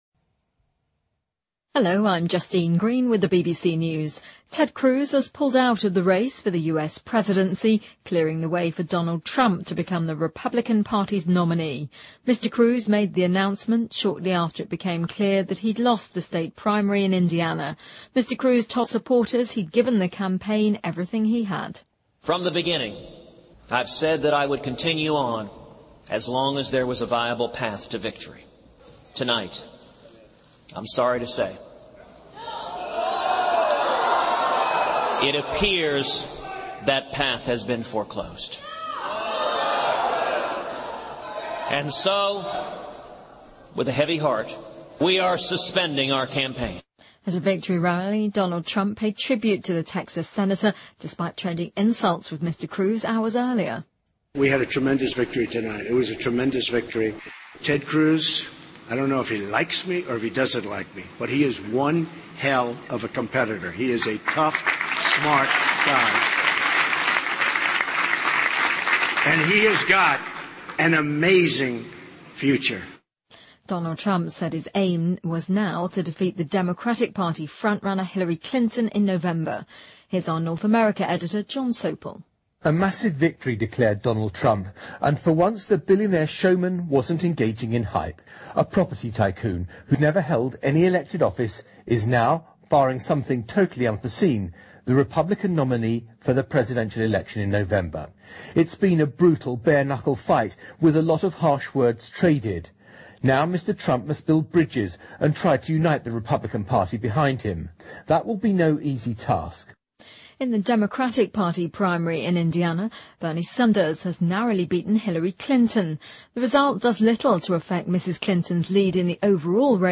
BBC news,克鲁兹退选，为特朗普共和党提名扫清障碍